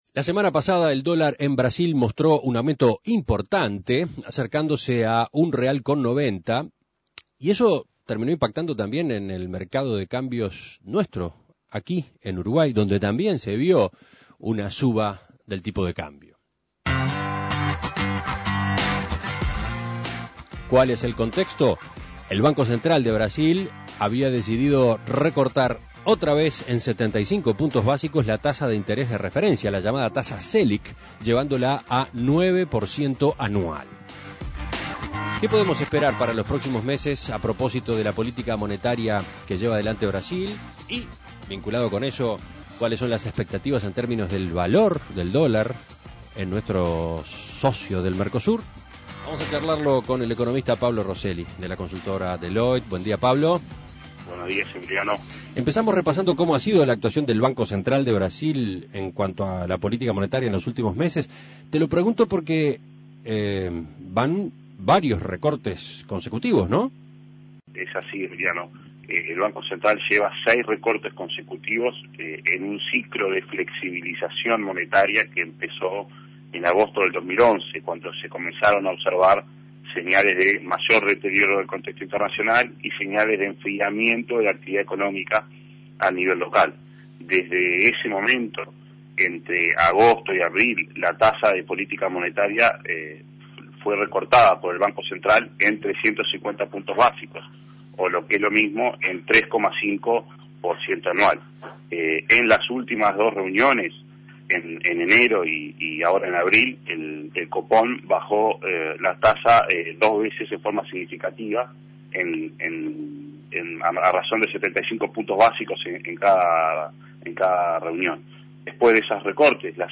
Análisis Económico El Banco Central de Brasil recortó nuevamente la tasa Selic.